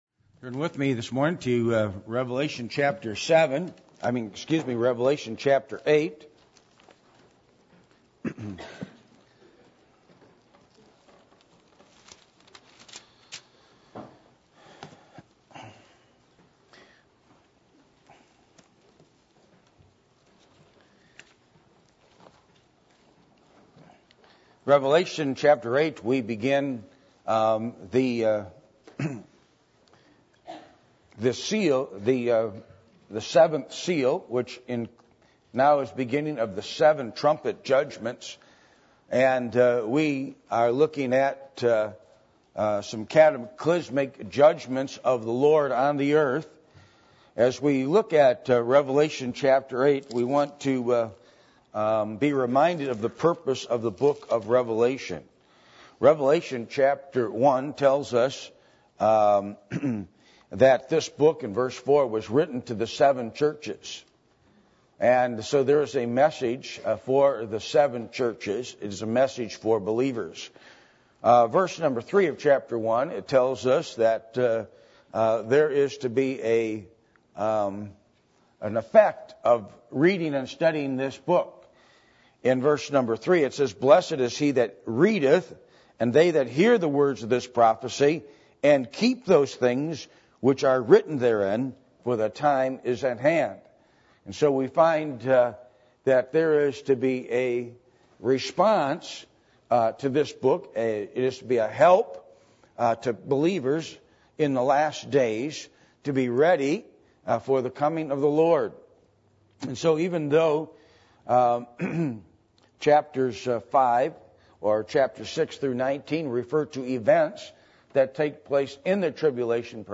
Passage: Revelation 8:1-13 Service Type: Sunday Morning